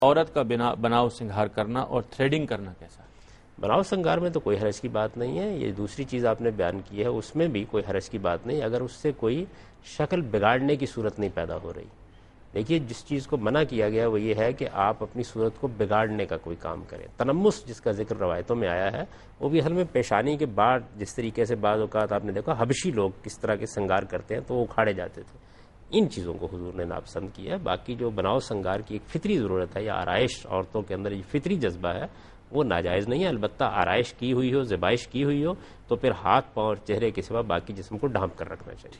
Answer to a Question by Javed Ahmad Ghamidi during a talk show "Deen o Danish" on Dunya News TV